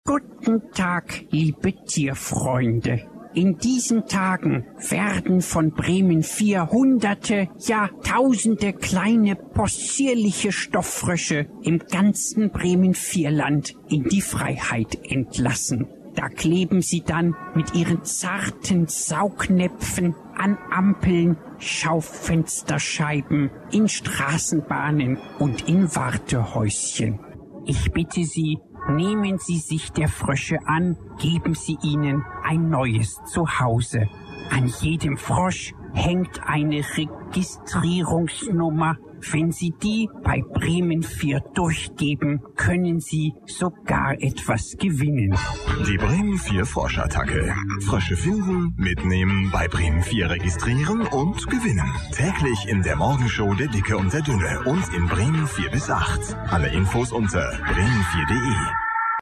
Für die Bremen Vier Frosch-Attacke im September und Oktober 2008 war im Programm von Bremen Vier ein Trailer mit einer altbekannten Stimme zu hören: Professor Dankwart von Hinten kündigte die Aktion im "Verblüffende Phänomene"-Stil an.
Der Trailer als MP3